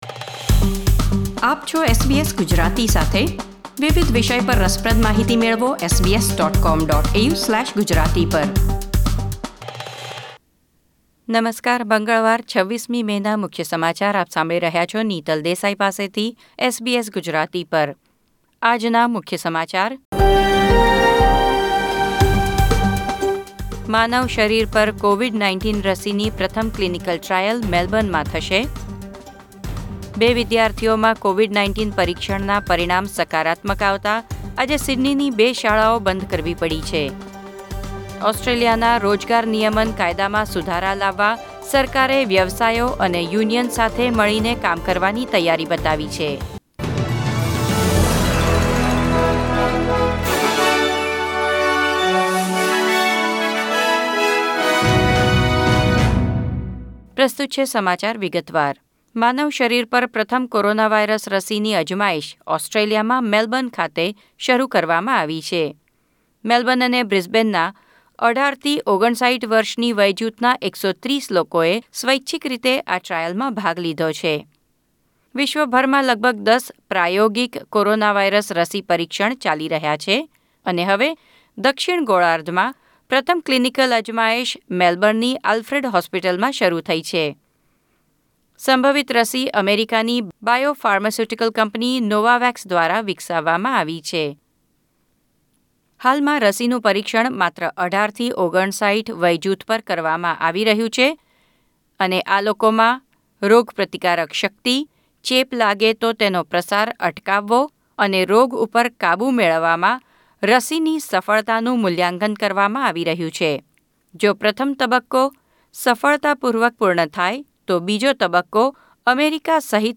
SBS Gujarati News Bulletin 26 May 2020